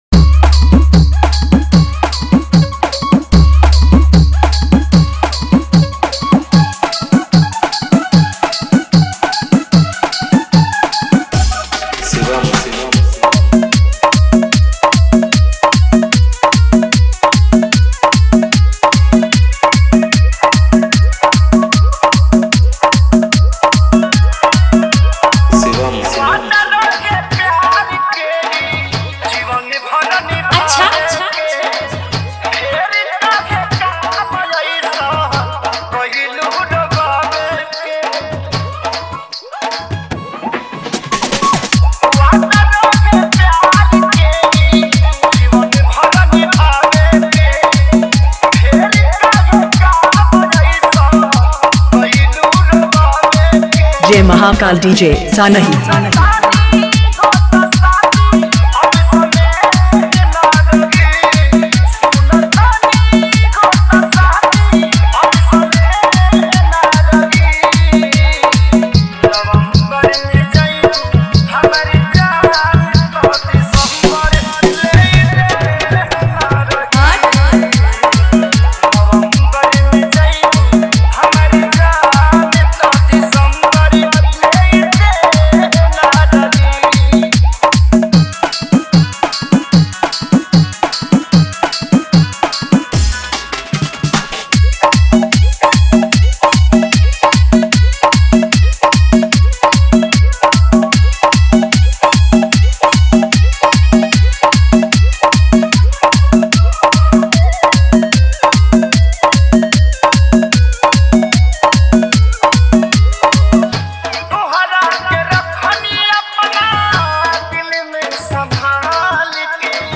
Bhojpuri Dj Songs